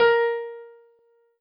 piano-ff-50.wav